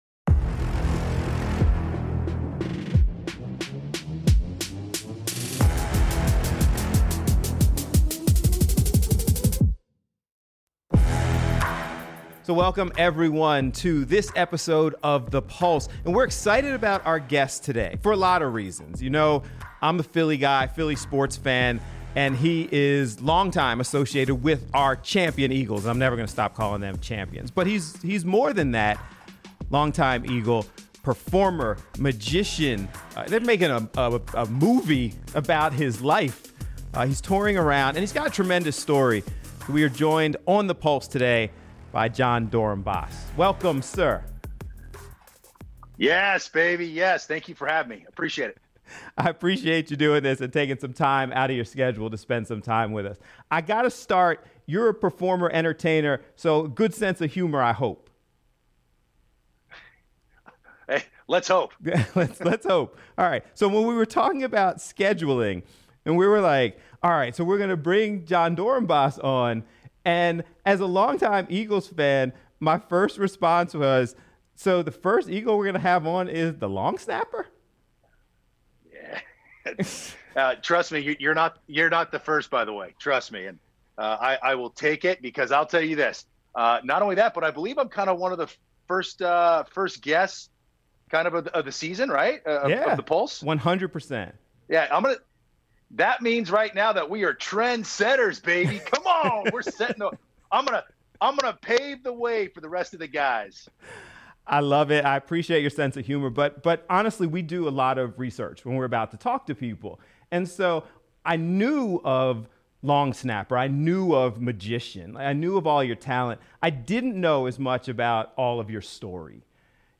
features topical discussion and interviews surrounding current issues, events, and entertainment.